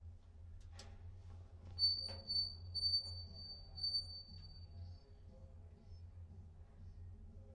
数字电话铃音
描述：数字电话铃声
Tag: 数字 PHO NE